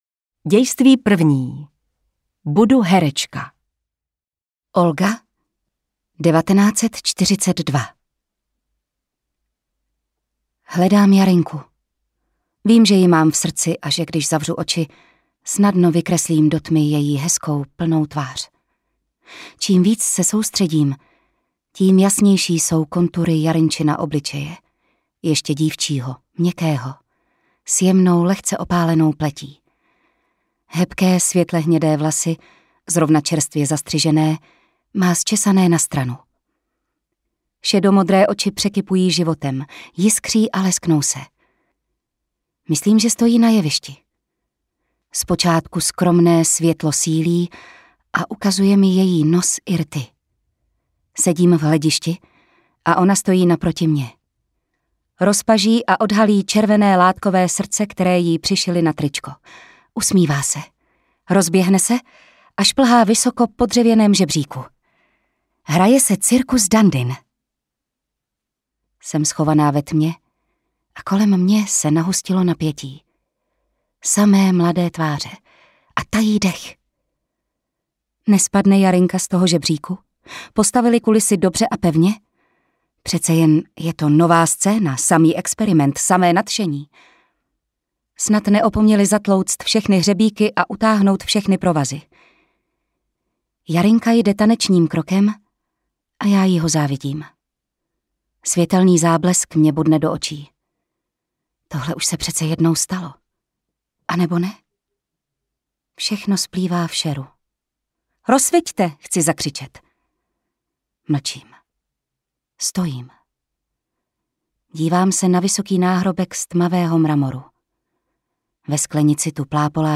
Herečka (audiokniha)